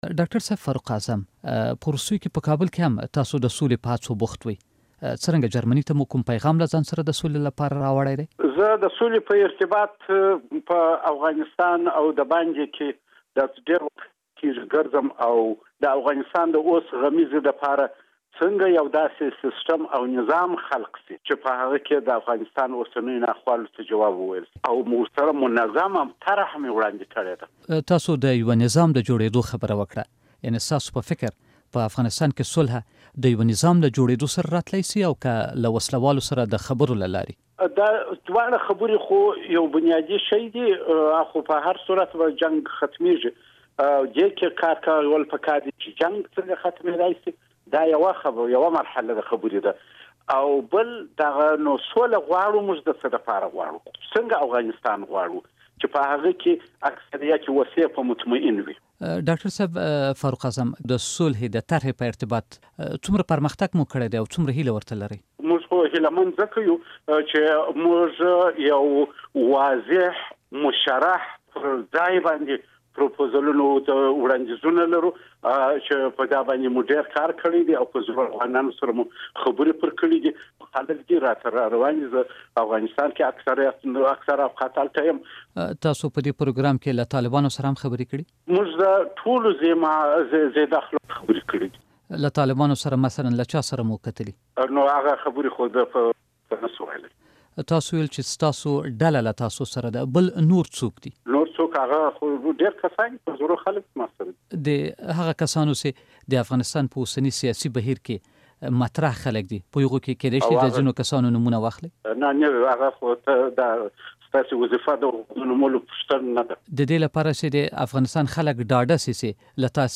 په افغانستان کې د سولې پر هڅو مرکه